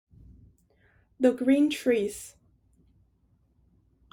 river1.wav